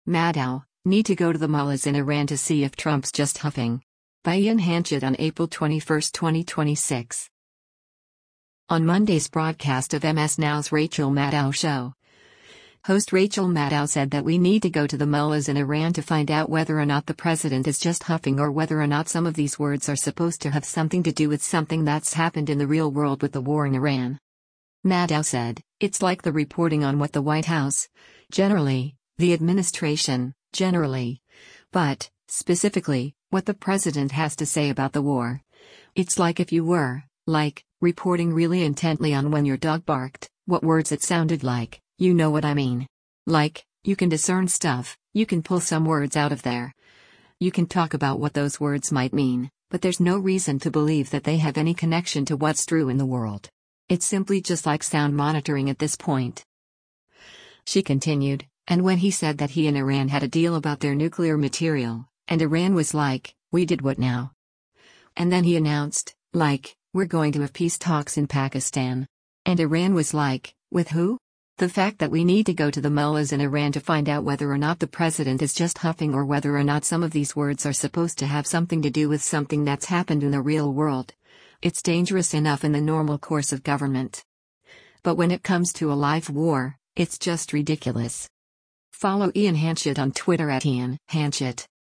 On Monday’s broadcast of MS NOW’s “Rachel Maddow Show,” host Rachel Maddow said that “we need to go to the mullahs in Iran to find out whether or not the president is just huffing or whether or not some of these words are supposed to have something to do with something that’s happened in the real world” with the war in Iran.